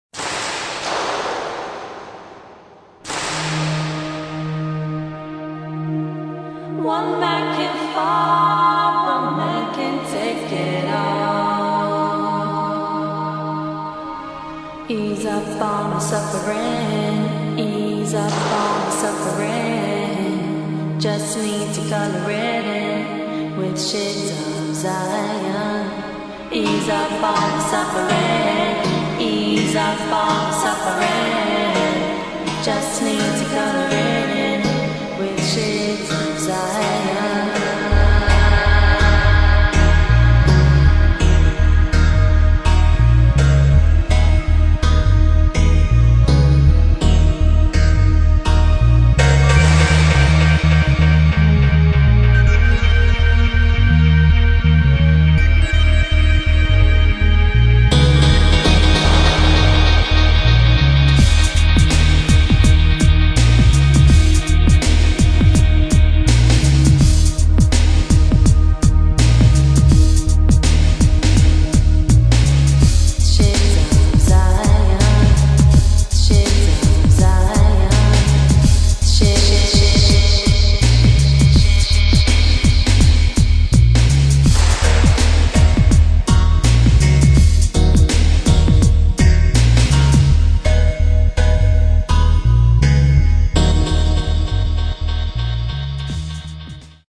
[ REGGAE / DUB ] フィメール・シンガー